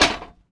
metal_hit_small_05.wav